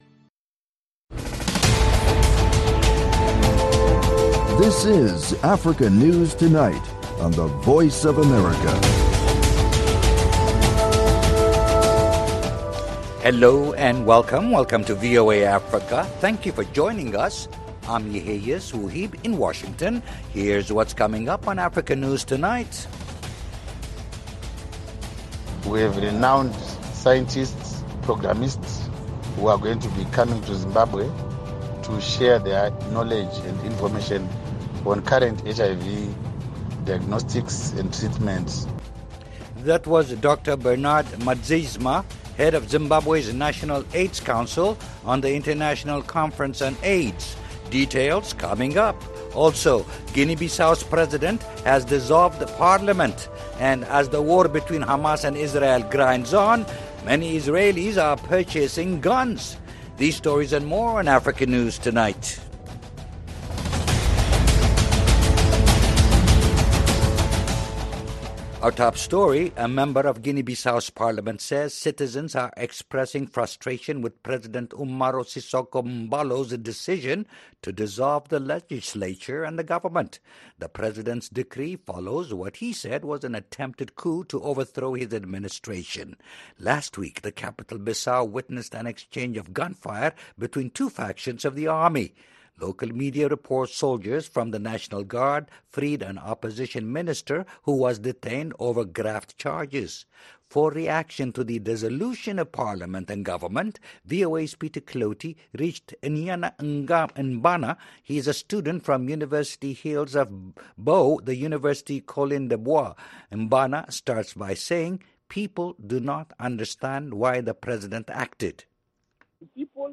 Africa News Tonight is a lively news magazine show featuring VOA correspondent reports, interviews with African officials, opposition leaders, NGOs and human rights activists. News feature stories look at science and technology, environmental issues, humanitarian topics, and the African diaspora.